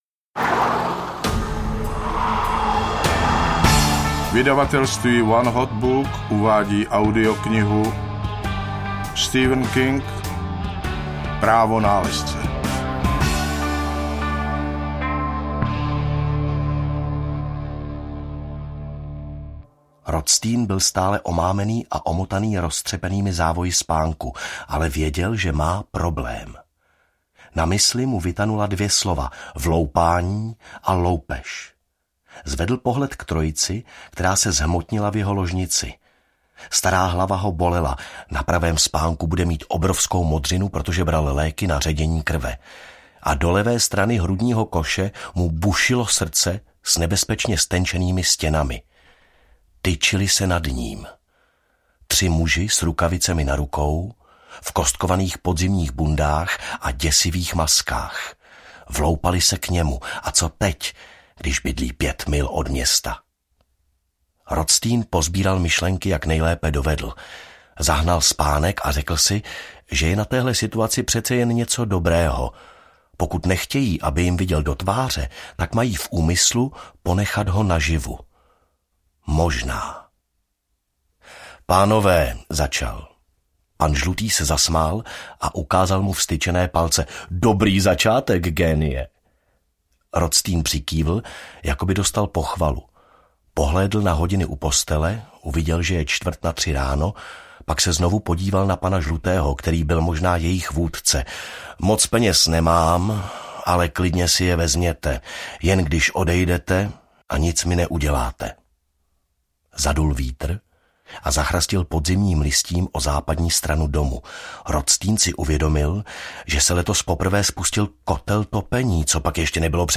Právo nálezce audiokniha
Ukázka z knihy